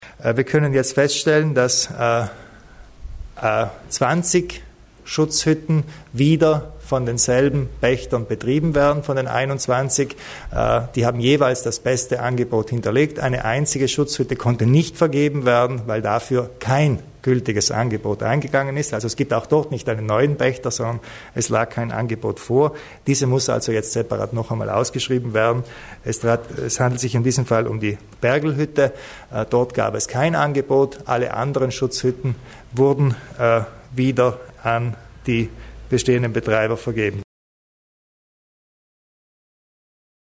Landeshauptmann Kompatscher zur Vergabe der Schutzhütten